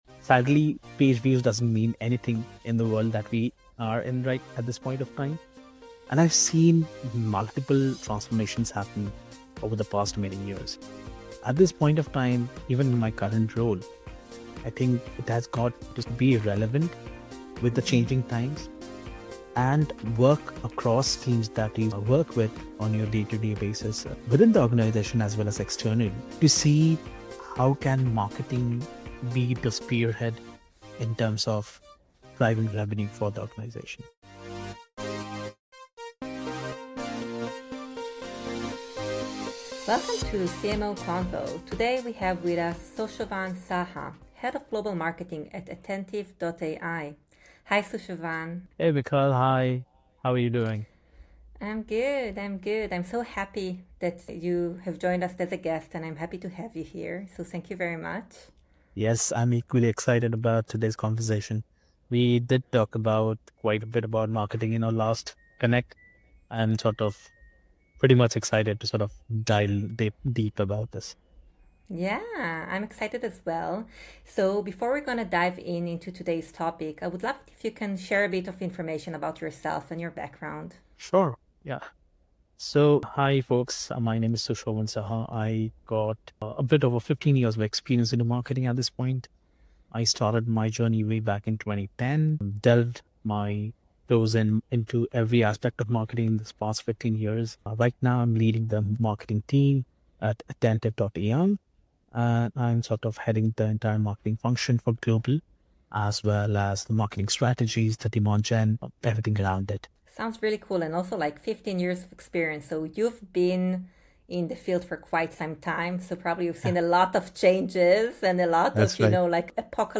1 Weekend Edition: Tour de France - what does it take to conquer the world's toughest race? 37:16 Play Pause 1h ago 37:16 Play Pause Play later Play later Lists Like Liked 37:16 Welcome to the new-look weekend edition with fresh interviews and content!